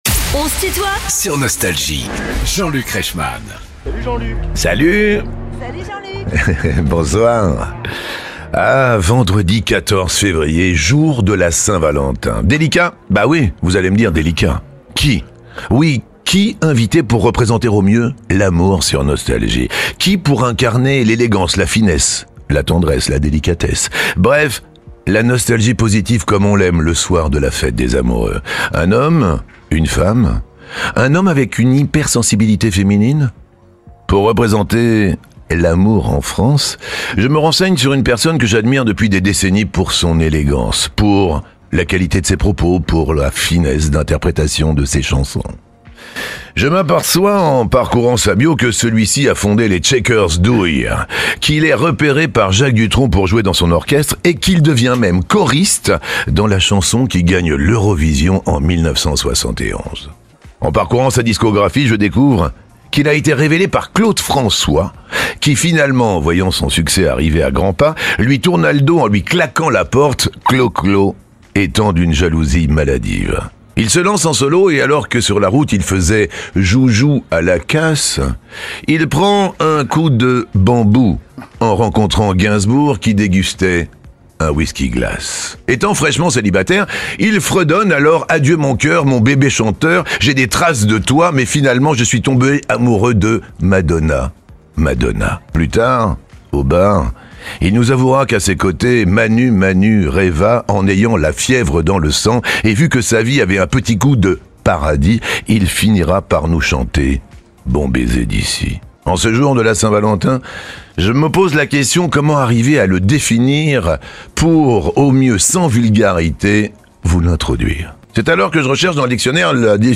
Alain Chamfort est l'invité de "On se tutoie ?..." avec Jean-Luc Reichmann
Les plus grands artistes sont en interview sur Nostalgie.